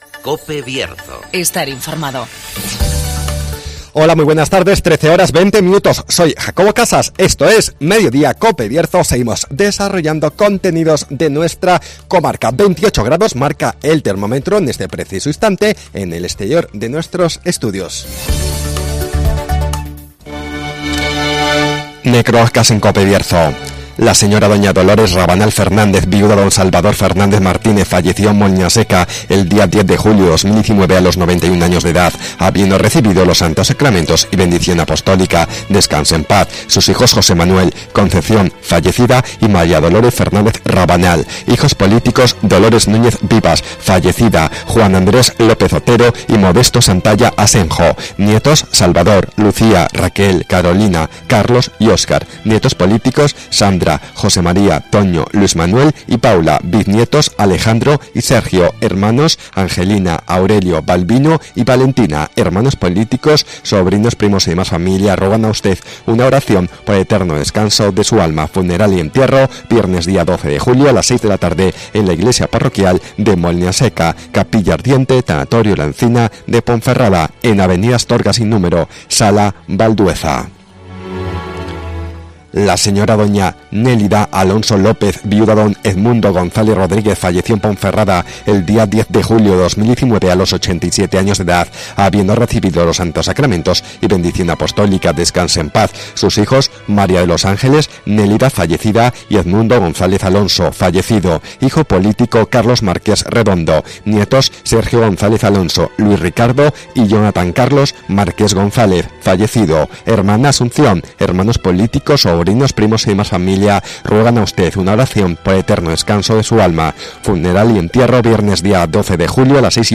Programas Bierzo ACTUALIDAD Mediodía COPE Bierzo 11-07-19 de 13:20 a 13:30 horas Repasamos la actualidad y realidad del Bierzo. Espacio comarcal de actualidad, entrevistas y entretenimiento.